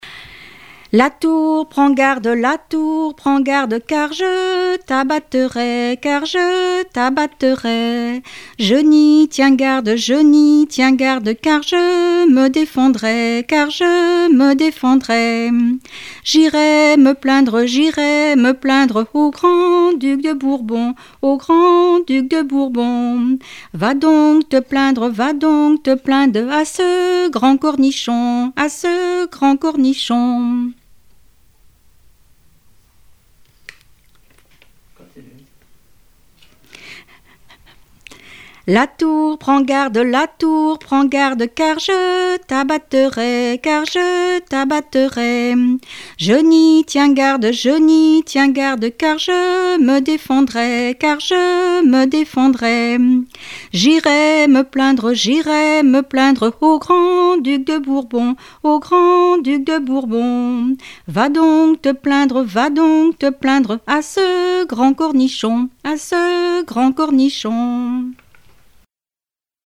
Saint-Gilles-Croix-de-Vie
Enfantines - rondes et jeux
Répertoire de chansons populaires et traditionnelles
Pièce musicale inédite